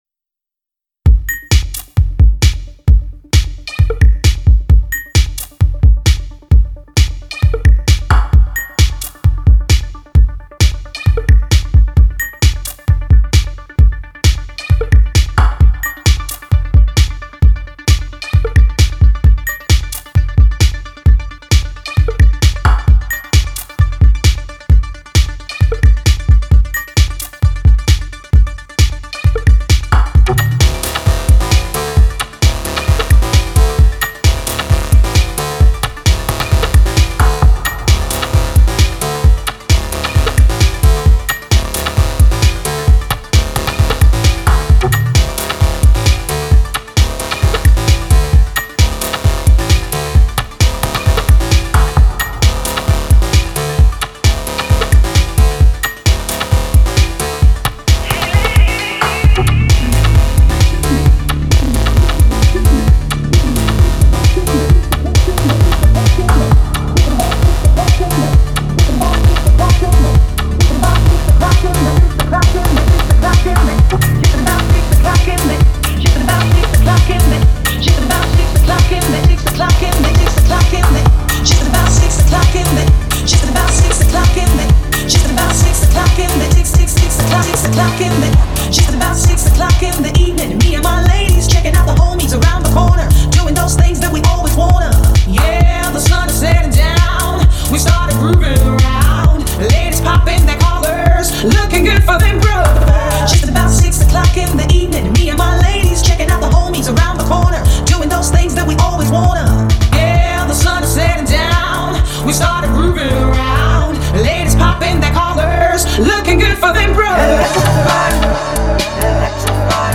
качество хорошее